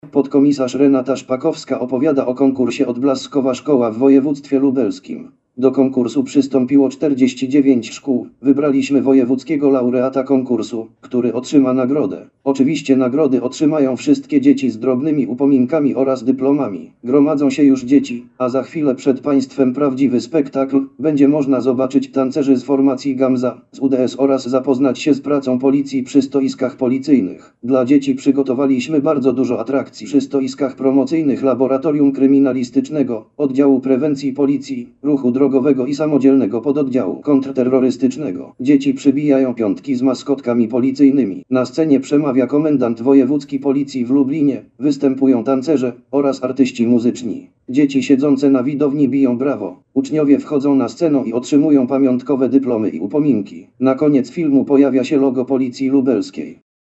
Nagranie audio Audiodeskrypcja filmu "Odblaskowa Szkoła"